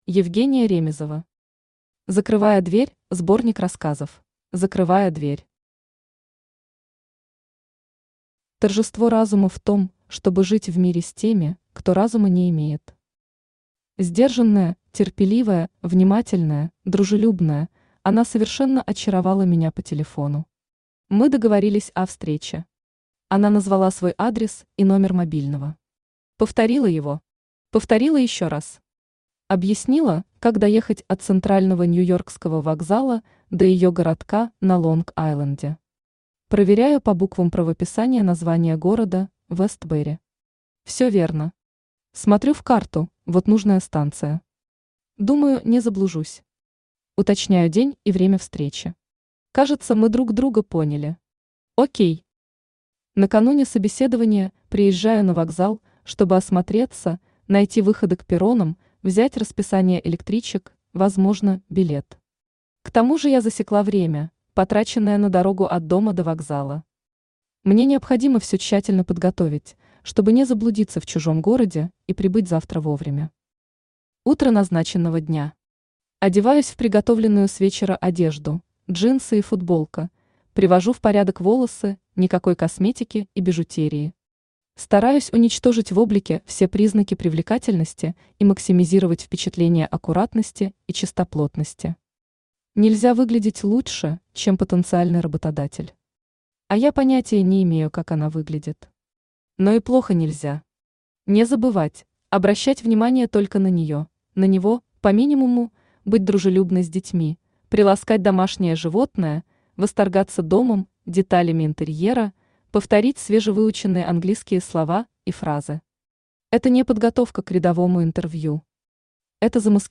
Aудиокнига Закрывая дверь (сборник рассказов) Автор Евгения Ремизова Читает аудиокнигу Авточтец ЛитРес.